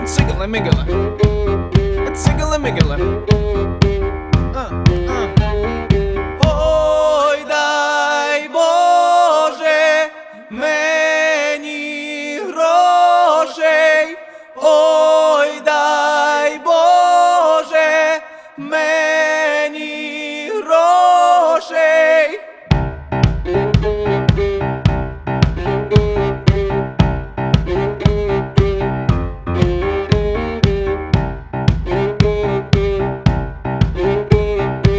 Жанр: Альтернатива / Украинский рок / Украинские